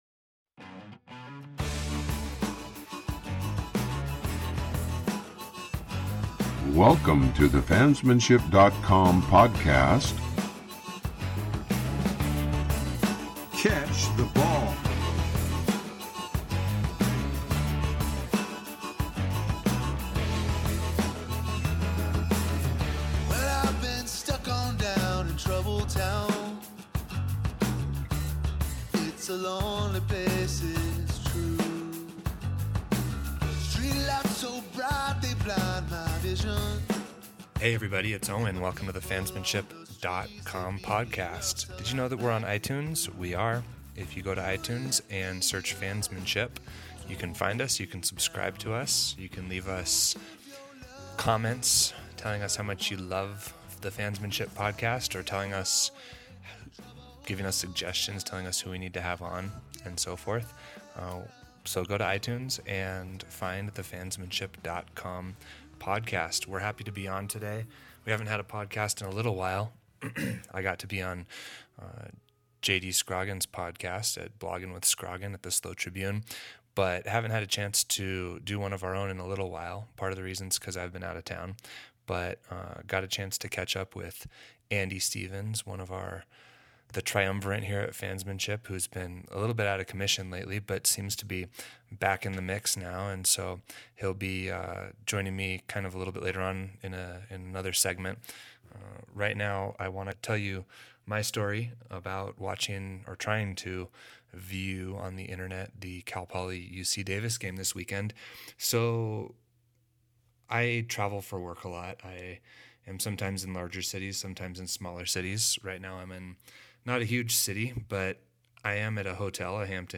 See if any of the voices in the outro music sound familiar to anyone.